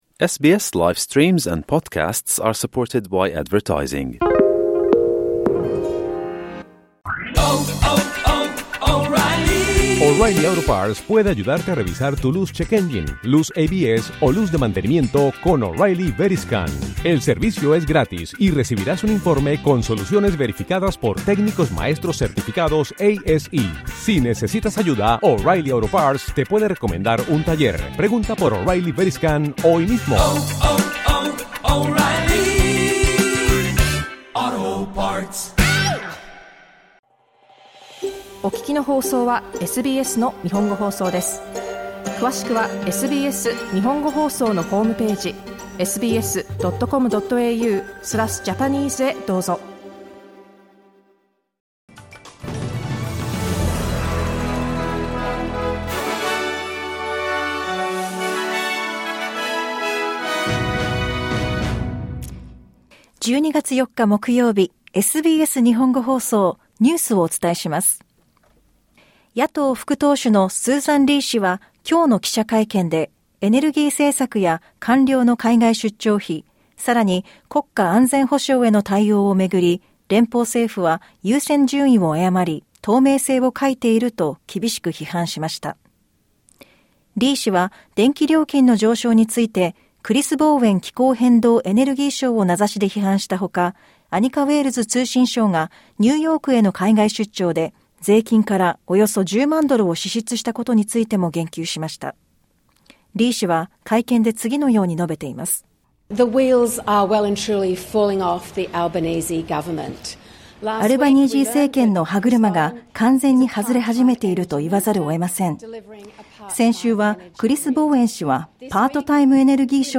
SBS Japanese News for Thursday 4 December